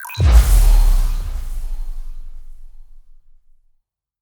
teleport.opus